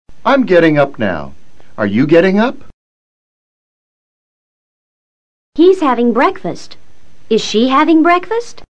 Entonación ascendente y descendente (I)
Las preguntas (oraciones interrogativas) que llevan respuesta con YES o NO, siempre terminan con entonación ASCENDENTE.
Las oraciones comunes (afirmativas o negativas) terminan con entonación DESCENDENTE.